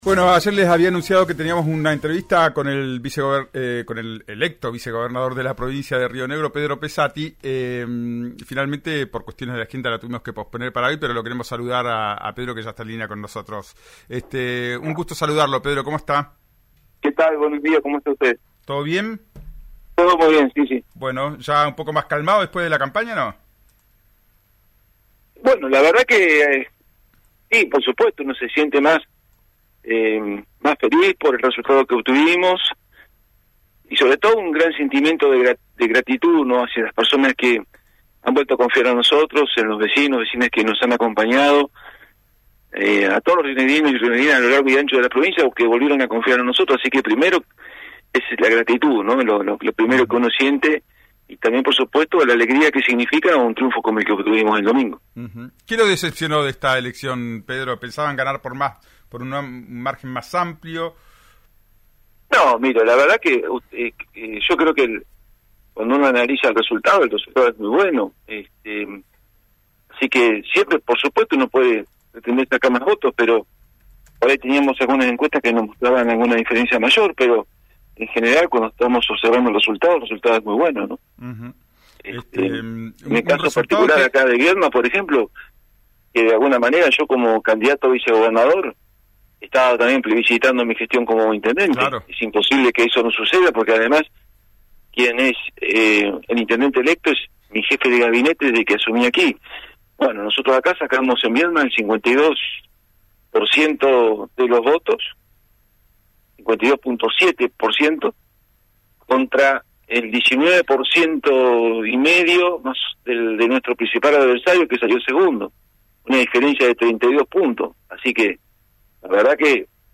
El futuro vicegobernador de Río Negro y actual intendente de Viedma, Pedro Pesatti, habló con RÍO NEGRO RADIO tras el resultado electoral del domingo.
Escuchá al vicegobernador electo Pedro Pesatti en «Ya es tiempo», por RÍO NEGRO RADIO.